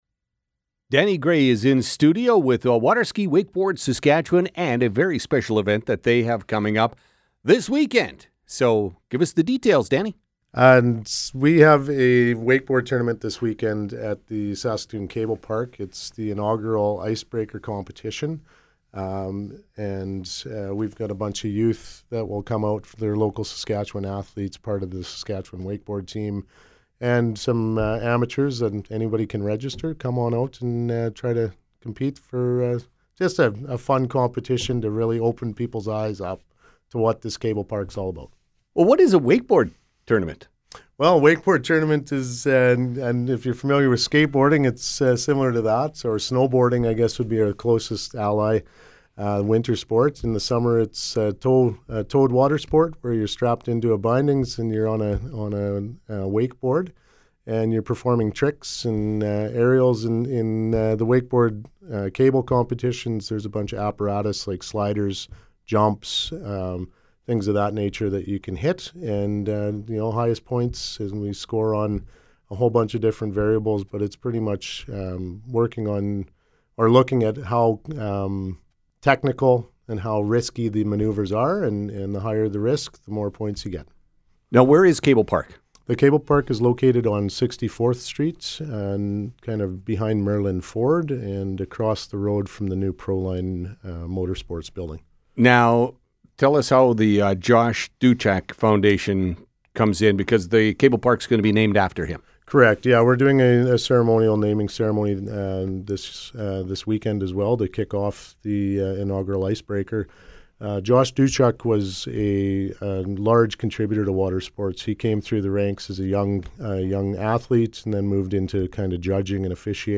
stopped by the studio to talk about the day….